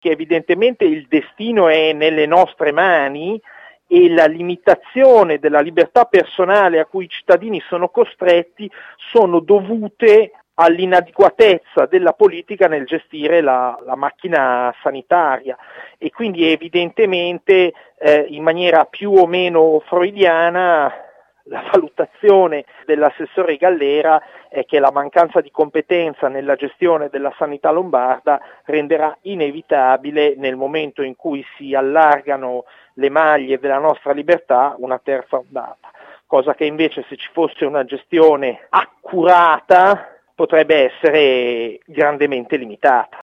Michele Usuelli, consigliere regionale di +Europa: